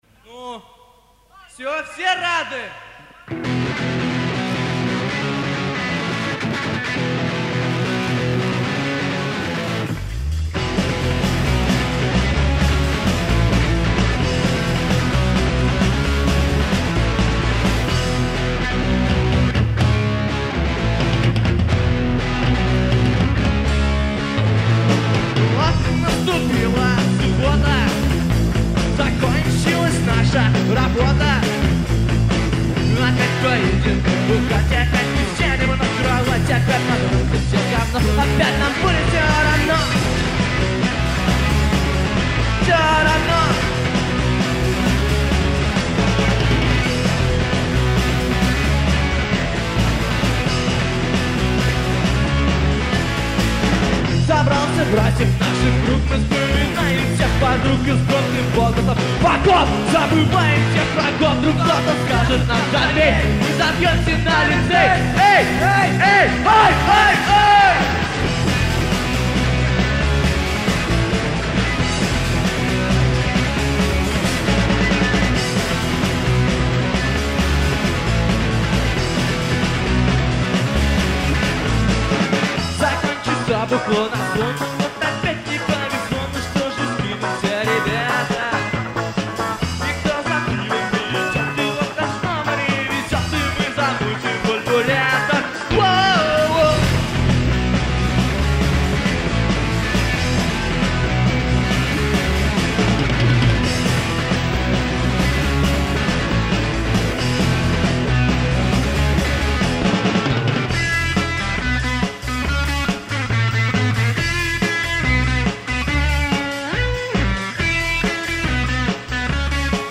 концертная весрия